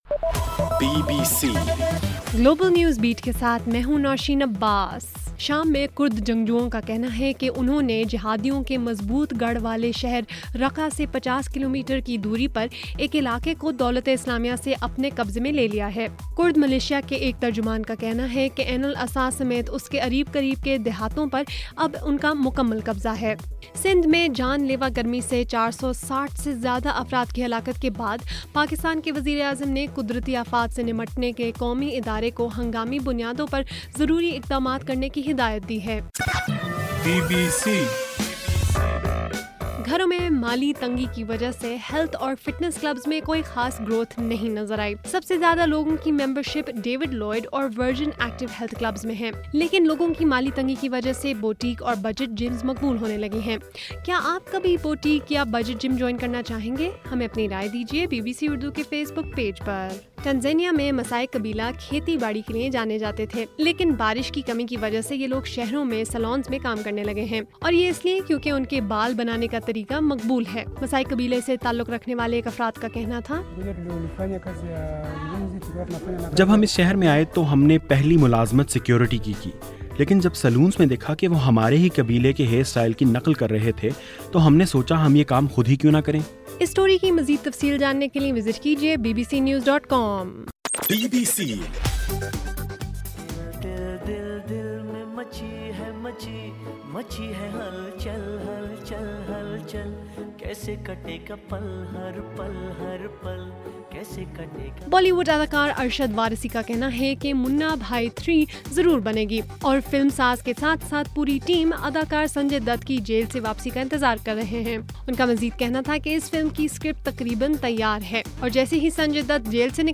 جون 23: رات 9 بجے کا گلوبل نیوز بیٹ بُلیٹن